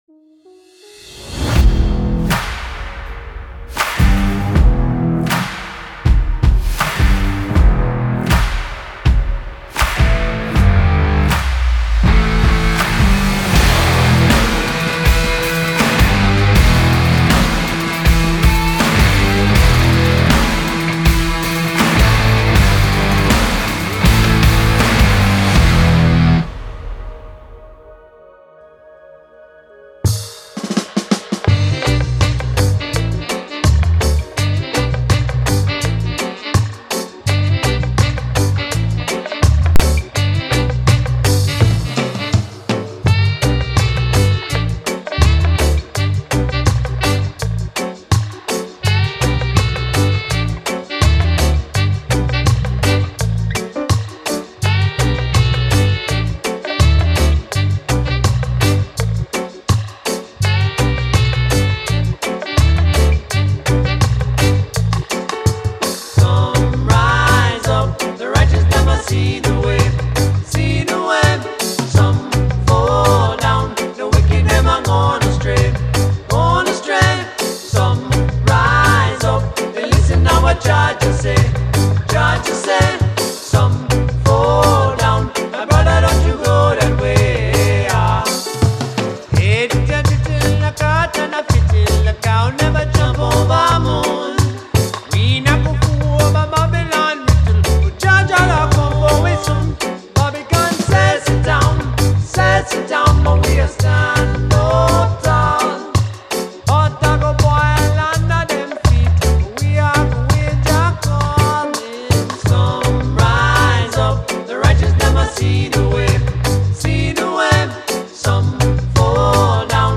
Musikalische Kreationen (Remixe)